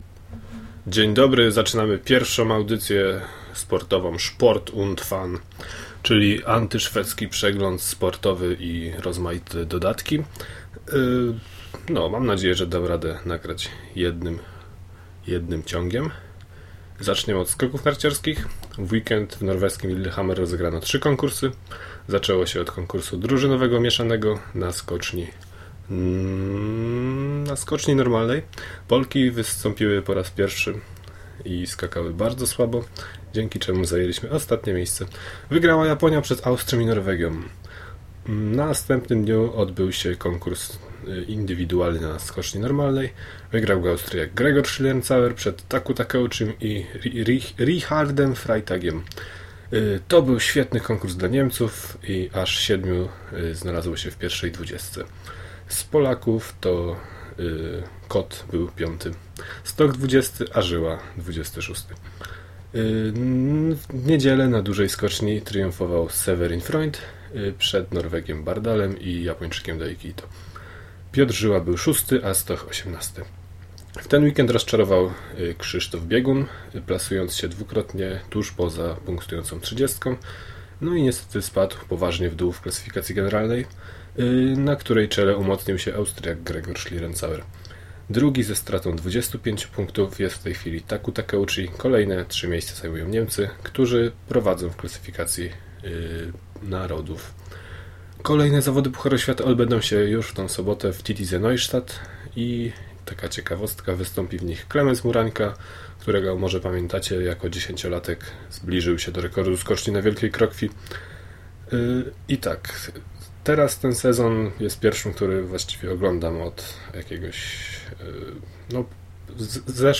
gąbka była, nagrywałem zaraz koło laptopa, może dlatego